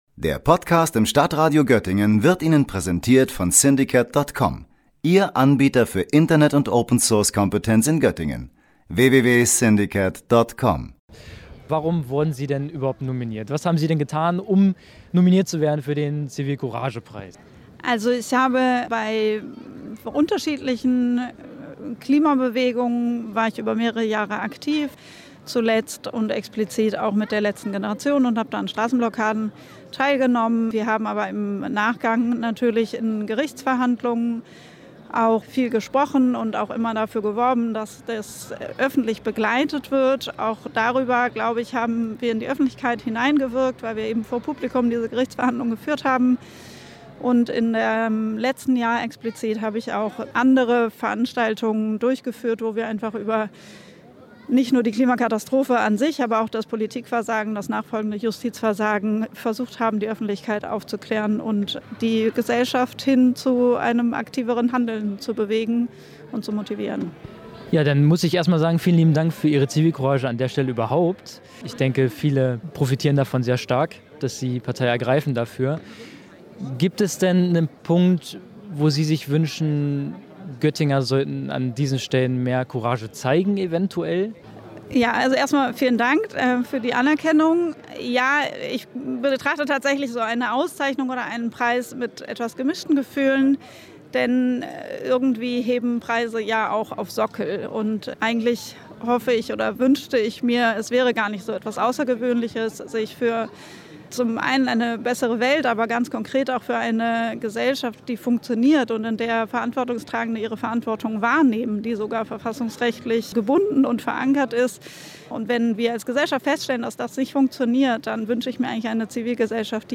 Beiträge > Lebhafte Stimmung bei der alternativen Preisverleihung des Zivilcouragepreises - StadtRadio Göttingen
Statt einer offiziellen Preisverleihung der Bürgerstiftung, fand am Donnerstag beim Gänseliesel eine inoffizielle Würdigung statt. Sie wurde von einer unabhängigen Gruppe aus Bürgerinnen und Bürgern selbst organisiert.